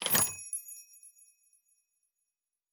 Prize Chest (2).wav